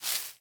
Minecraft Version Minecraft Version 25w18a Latest Release | Latest Snapshot 25w18a / assets / minecraft / sounds / block / azalea_leaves / step3.ogg Compare With Compare With Latest Release | Latest Snapshot